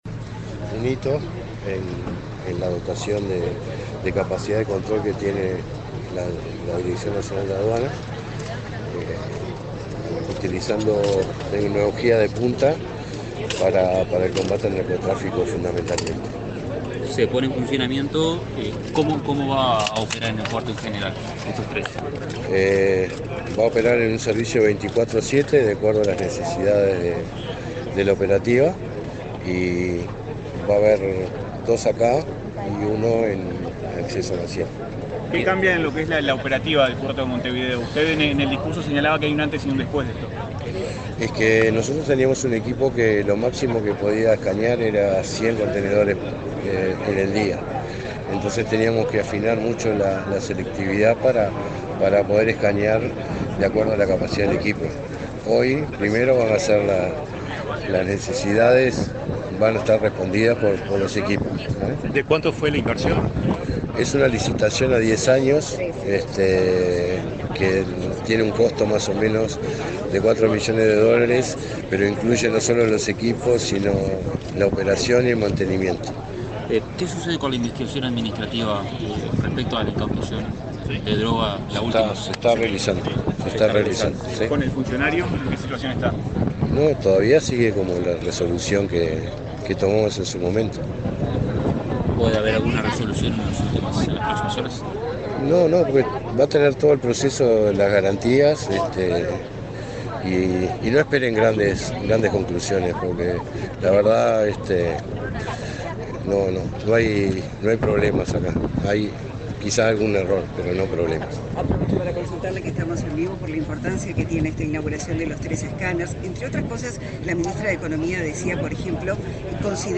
Declaraciones del director nacional de Aduanas, Jaime Borgiani
Declaraciones del director nacional de Aduanas, Jaime Borgiani 02/10/2024 Compartir Facebook X Copiar enlace WhatsApp LinkedIn Este miércoles 2, el director nacional de Aduanas, Jaime Borgiani, dialogó con la prensa, luego de participar en la inauguración de la operativa de nuevos escáneres en el puerto de Montevideo.